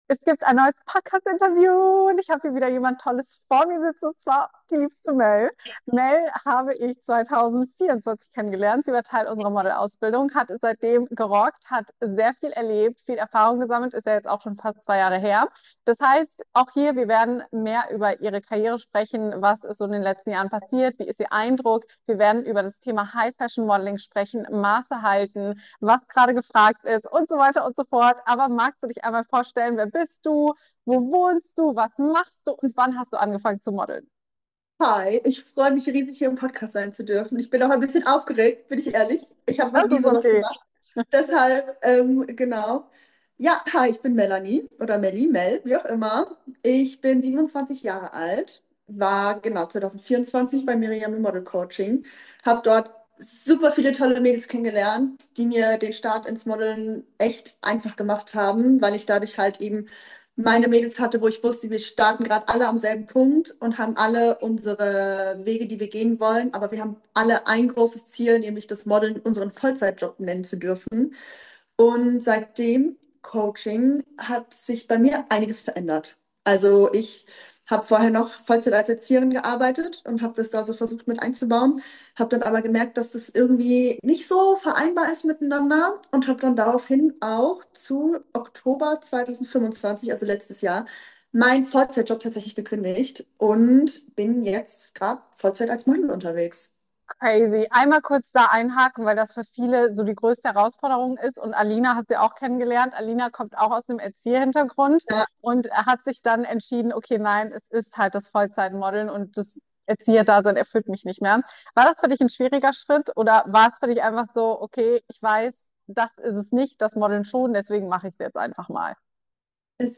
#318: Die unbequeme Wahrheit über die High-Fashion-Modelwelt - Interview